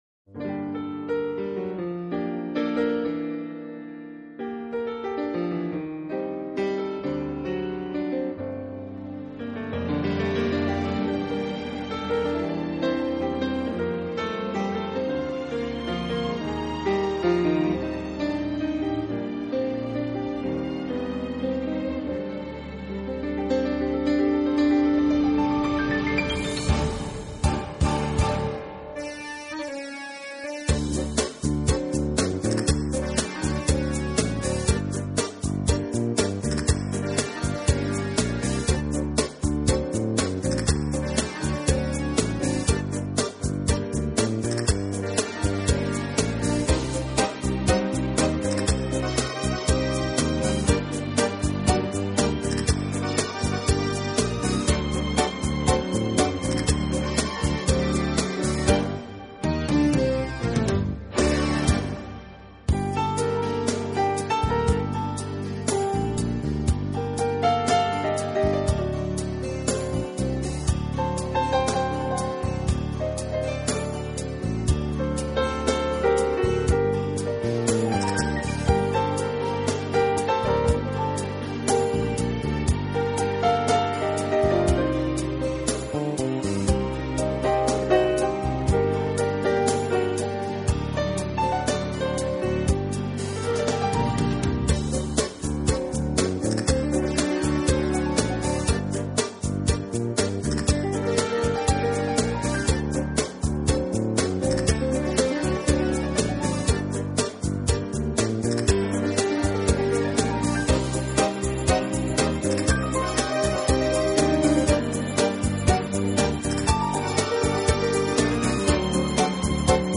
名品钢琴